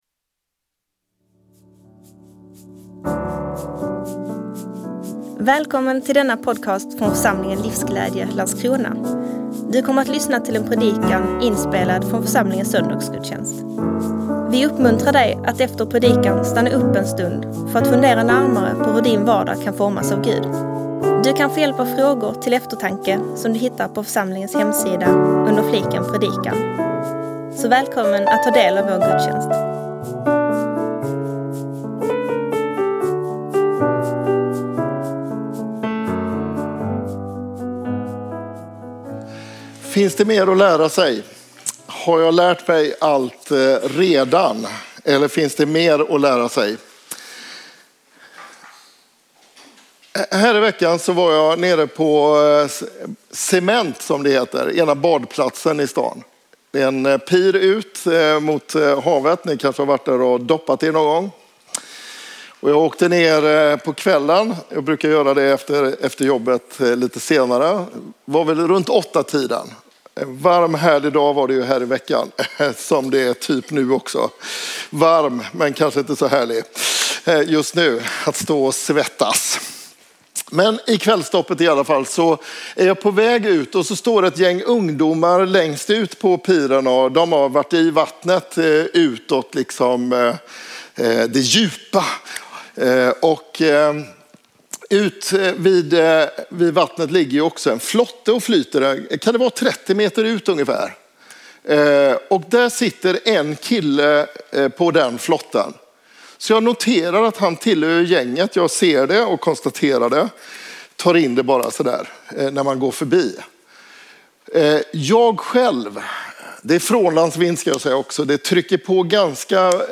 8 september 2024 I det vardagliga framträder det heliga Predikant